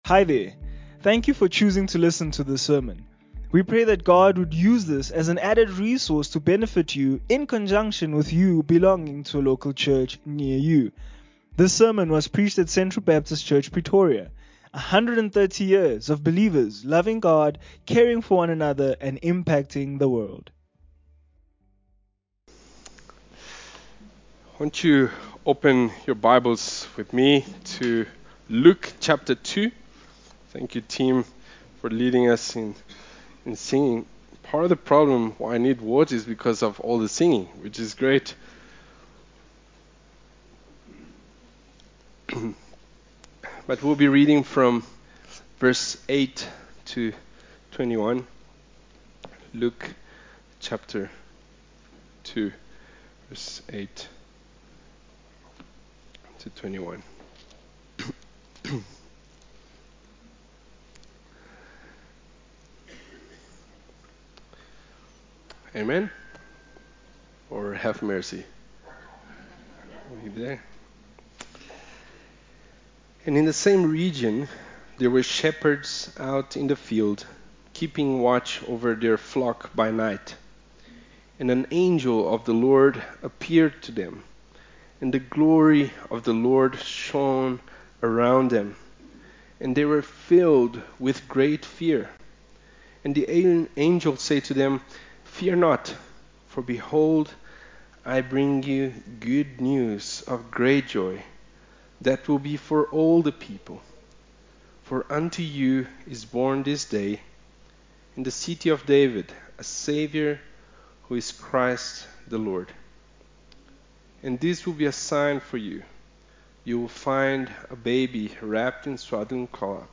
Sermons - Central Baptist Church Pretoria